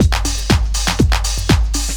Milky Beat 3_121.wav